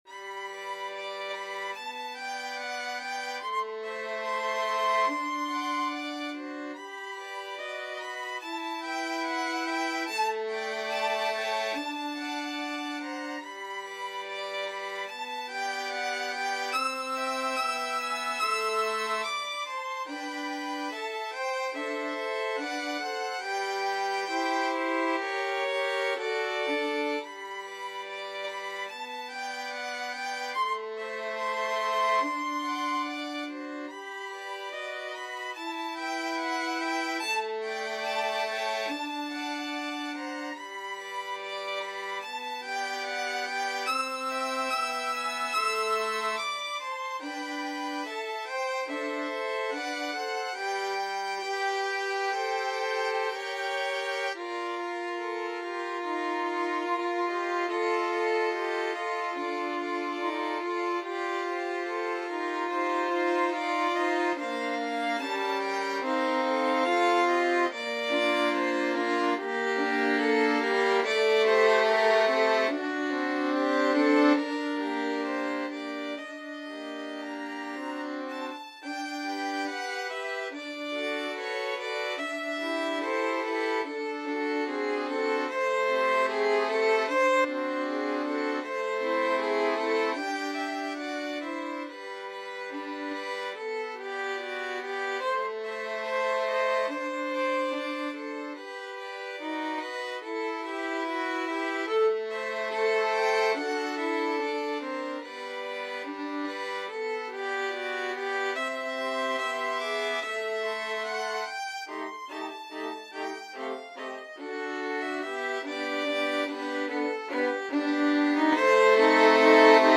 Free Sheet music for Violin Quartet
Violin 1Violin 2Violin 3Violin 4
G major (Sounding Pitch) (View more G major Music for Violin Quartet )
Andantino =72 (View more music marked Andantino)
Classical (View more Classical Violin Quartet Music)
elgar_salut_damour_4VLN.mp3